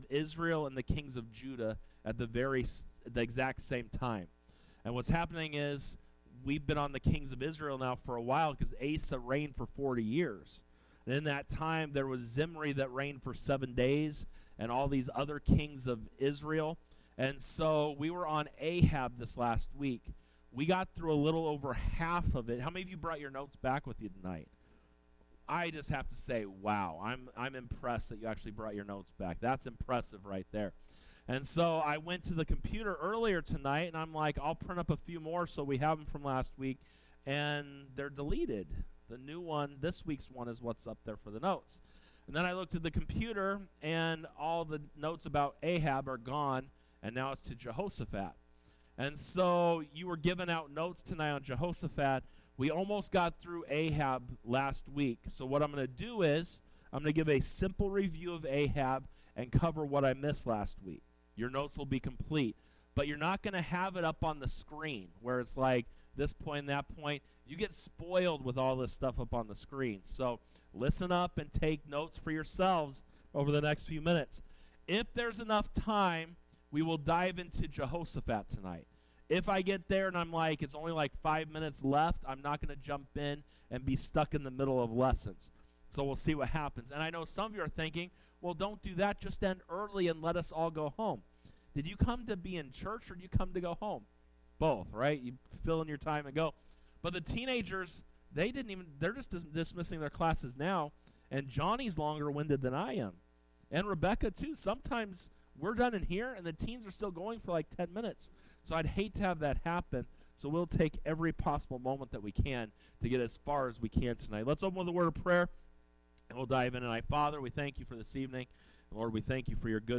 Wednesday Bible Study 02:05:25 - Lessons from the Life of King Ahab Part 2 by vbcchino